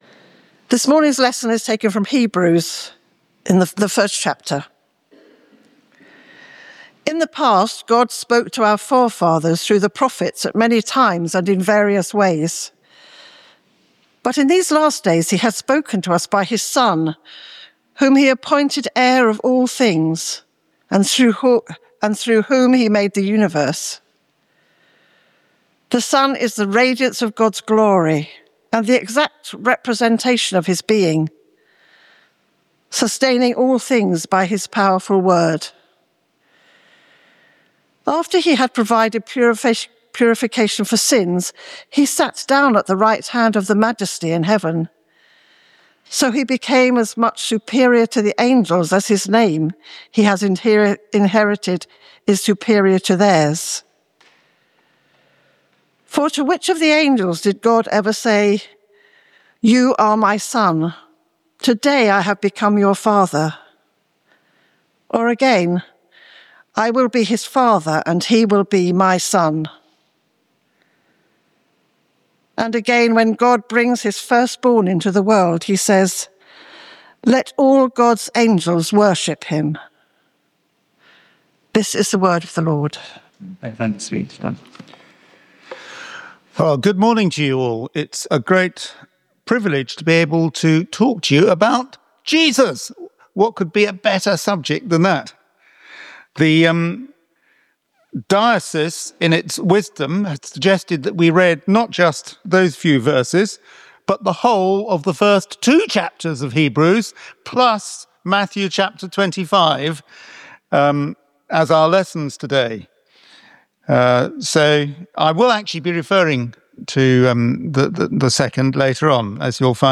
Holy Communion
Colossians 1:15-29 Service Type: Communion